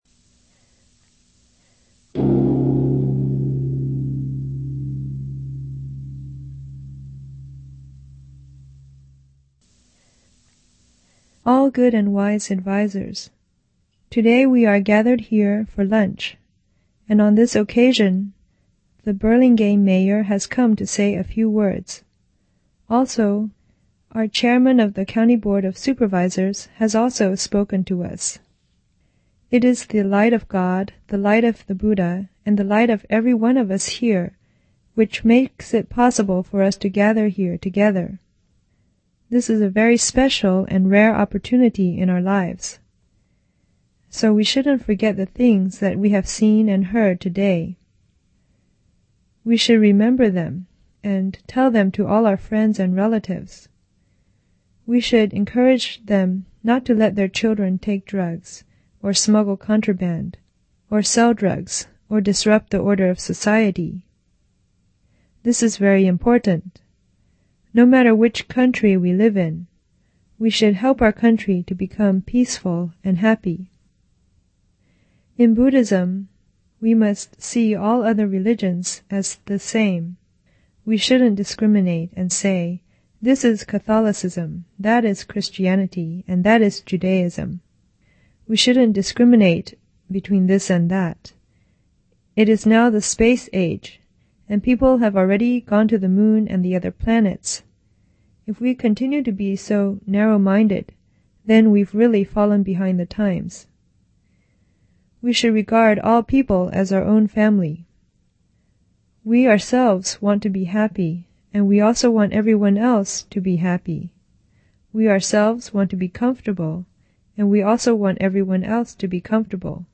Dharma Library : Instructional Talks from 1994